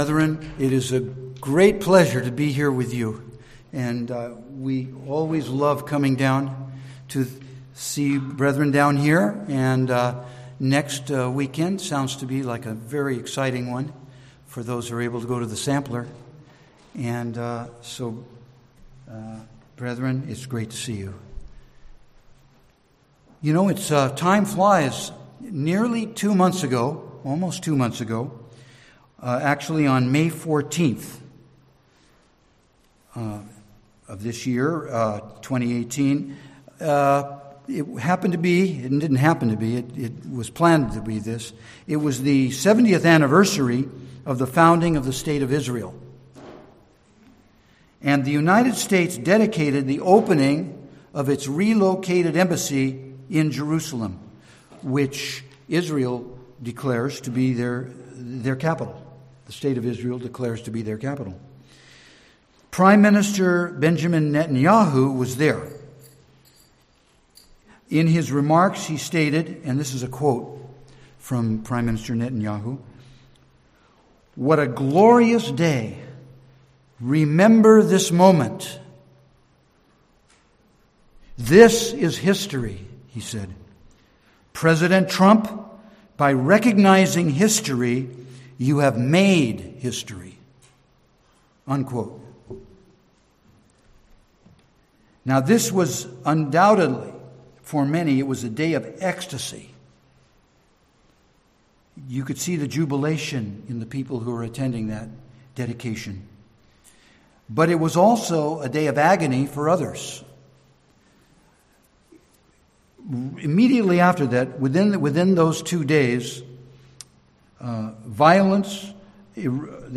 Sermons
Given in San Jose, CA